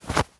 foley_cloth_light_fast_movement_09.wav